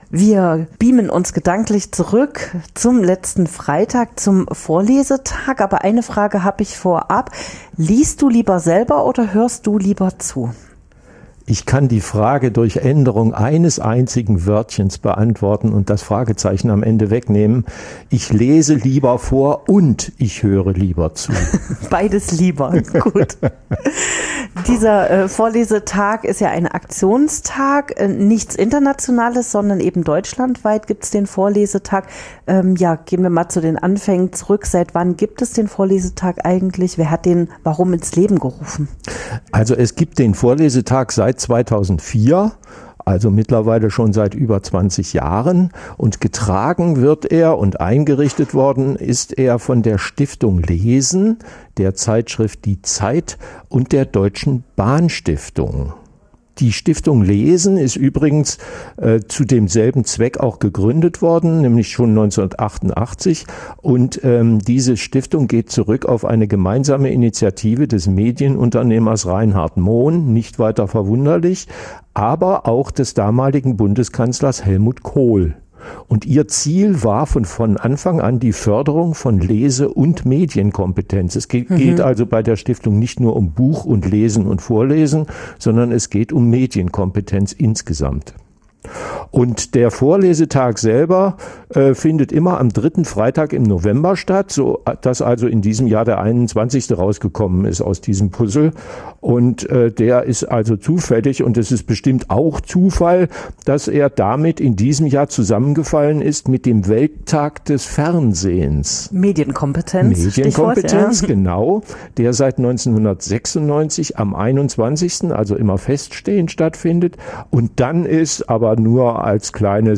Vorlesen - nicht nur für Kinder | Eindrücke vom Vorlesetag in Erfurt